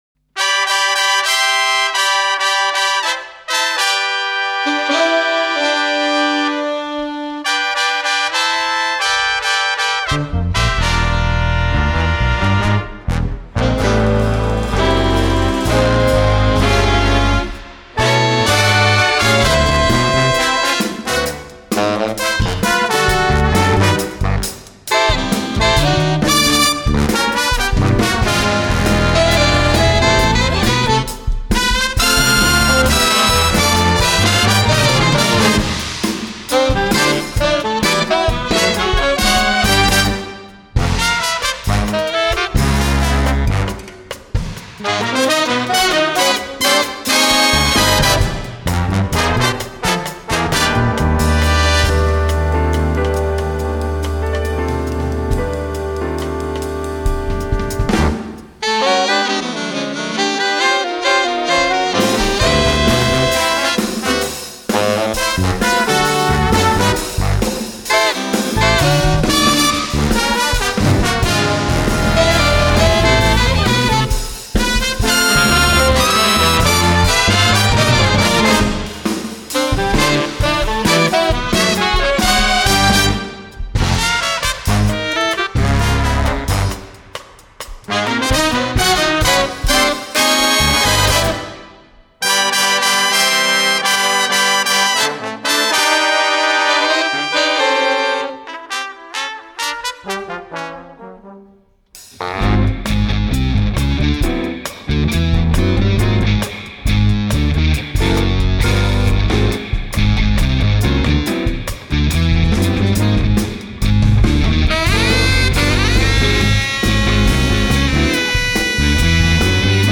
Standard big band line-up:
5 saxes AATTB
4 Trumpets
4 Trombones
Guitar
Bass
Drums